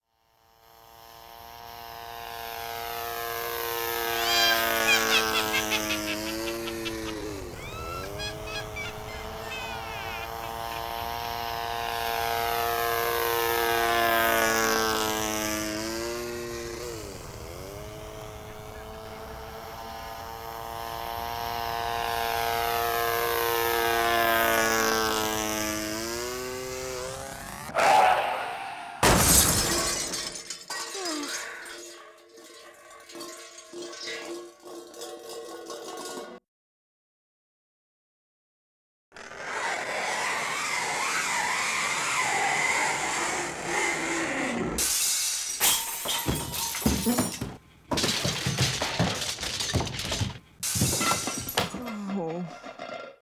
Soundtrack from our Witch Works display. ( right channel in the witche's house - left channel about 10 feet away ) 8 mB. flac format